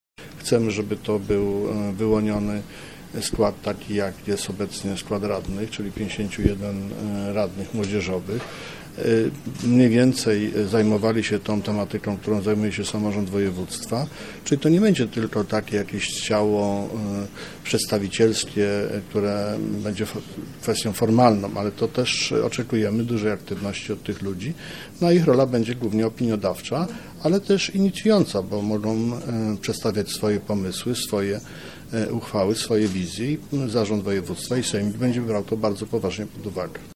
Wypowiedź Adama Struzika: